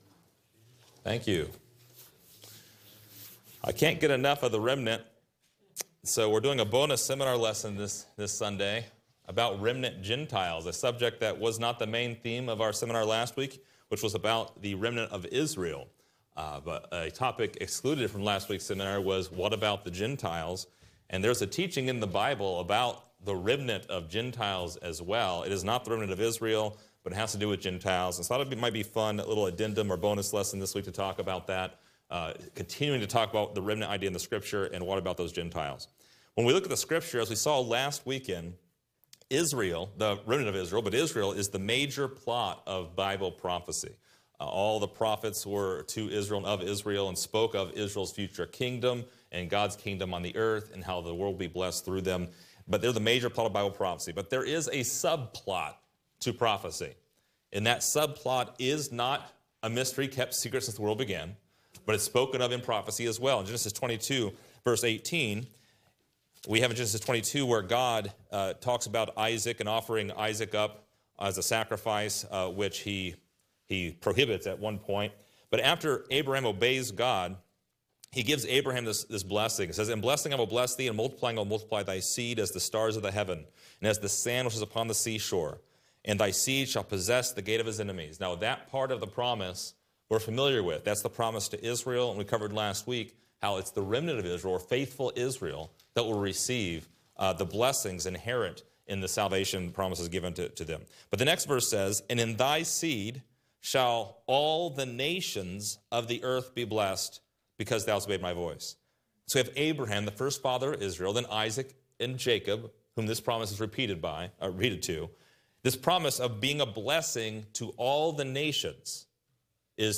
Download Outline Description: As an add-on to our seminar, this lesson deals with how a remnant of the Gentiles will be blessed through Israel in their kingdom – just as only a remnant of Israel inherits the kingdom. Find out more about the prophesied remnant of the Gentiles in this lesson!